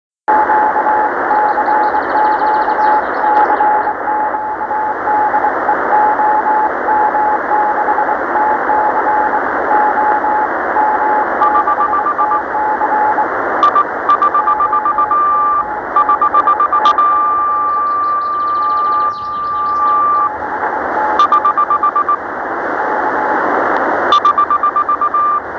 Сегодняшнее рандеву прослушивал на Tecsun PL-365 из МА-01, п/о, +26 °С, небольшой ветер.
Антенна 3 метра, выше на 0.5 м. чем в прошлый выход.